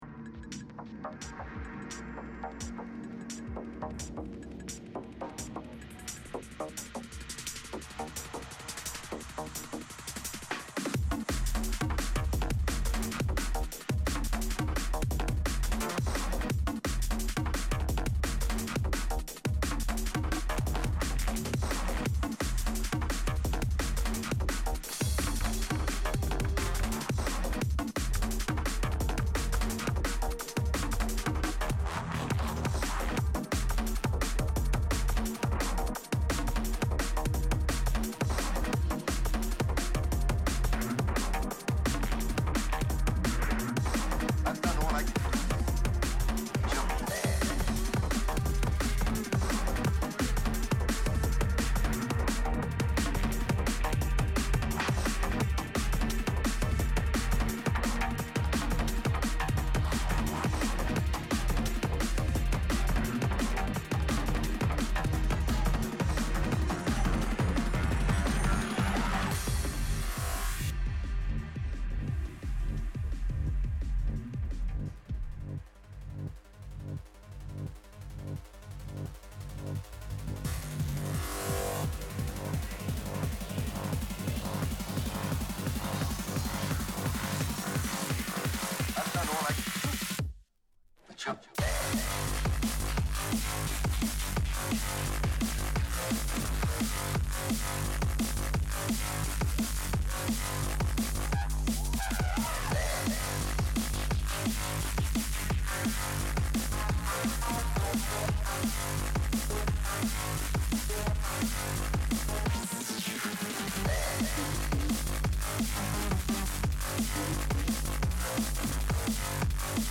31 Ottobre 2015 – Dj set INFO: DNB NEVER DIES!